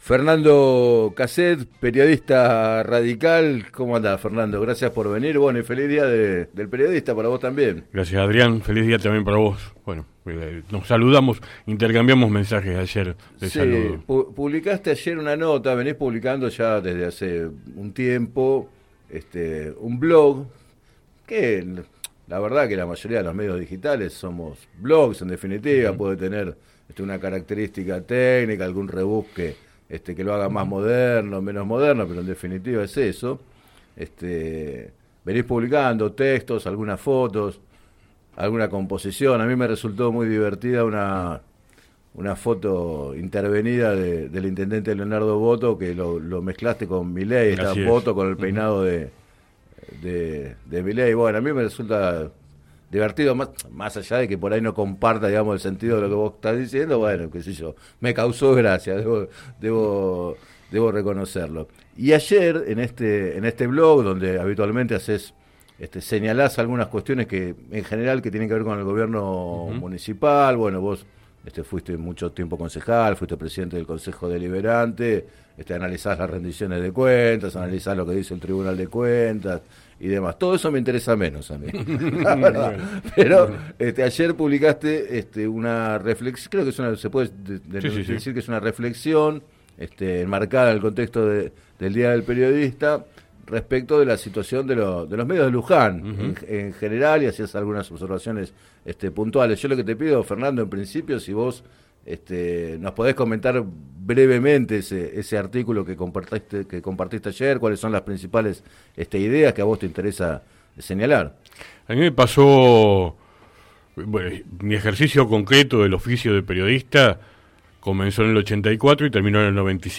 Entrevistado en el programa Planeta Terri